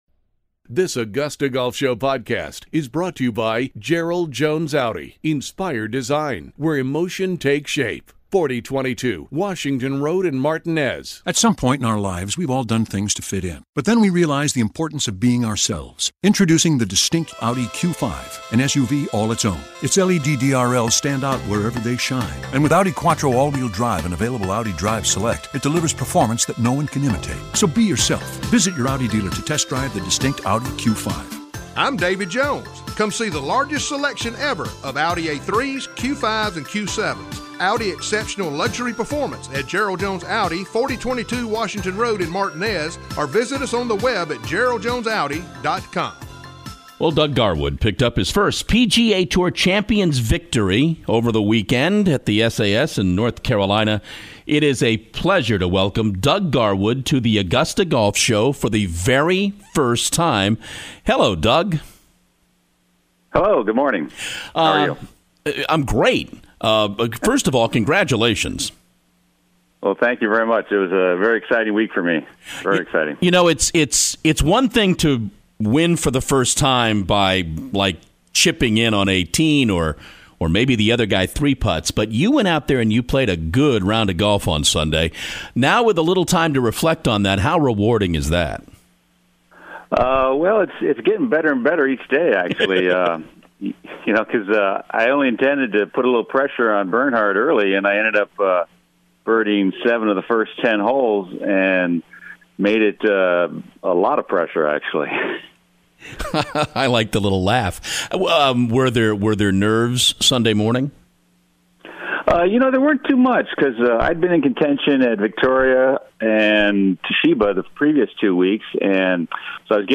Doug Garwood: The AGS Interview